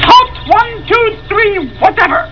: Grover says to halt.